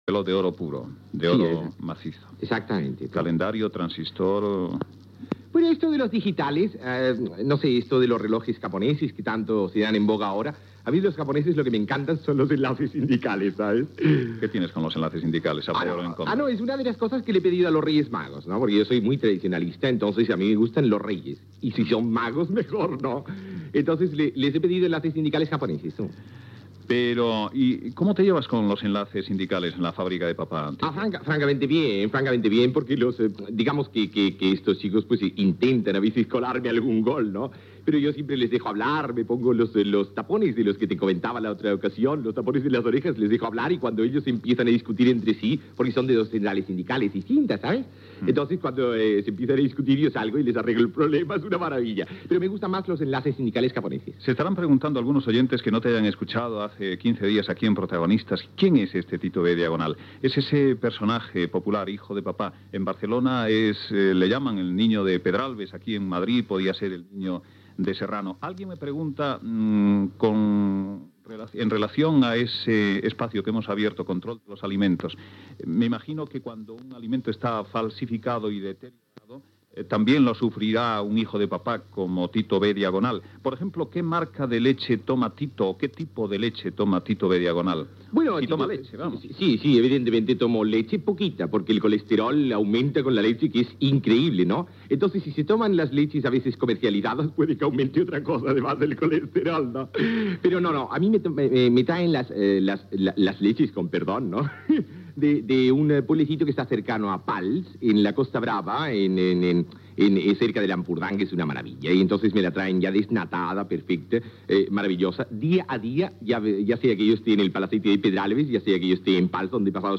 Segona entrevista a Tito B.Diagonal (Jordi Estadella). La seva relació amb els sindicats de l'empresa, la llet, la dècada dels 80 per al negoci tèxtil, on passarà les vacances de Nadal, què faria si fos alcalde, les seves inversions, el seu cotxe
Info-entreteniment